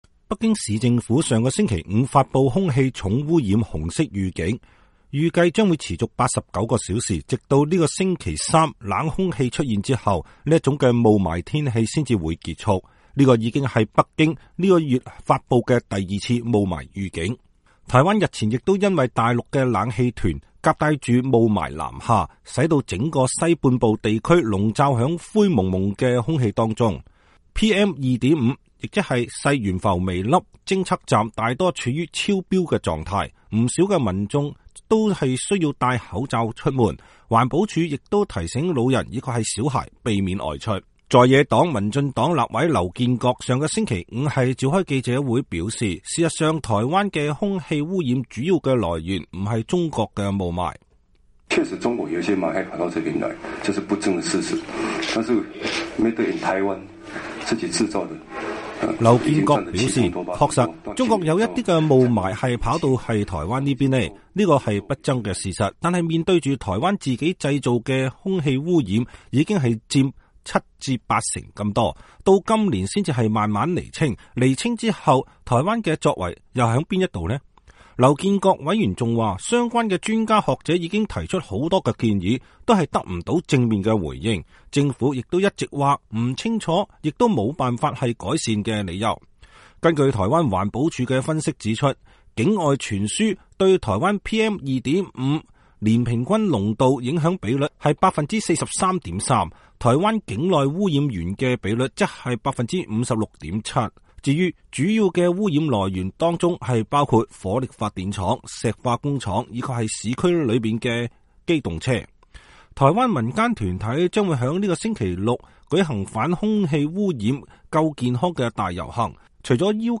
在野黨民進黨立委劉建國上週五召開記者會表示，事實上，台灣的空氣污染主要來源不是中國的霧霾。